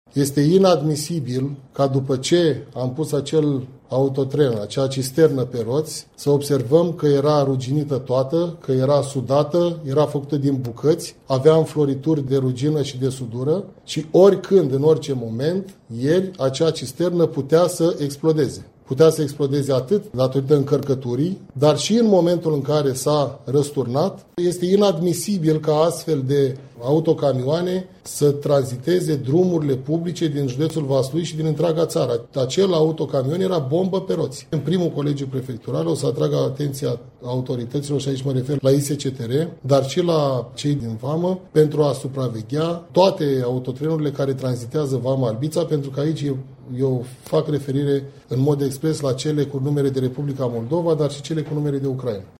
Prefectul judeţului Vaslui, Eduard Popica, a atras astăzi atenția, în cadrul unei conferinţe de presă, cu privire la starea tehnică improprie a unor vehiculele din Republica Moldova sau Ucraina, care tranzitează judeţul Vaslui.